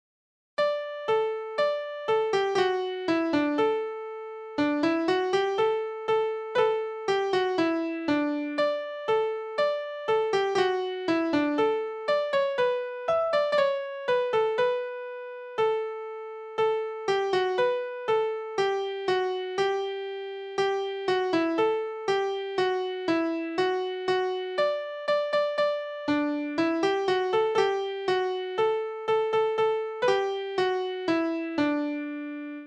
Chant grivois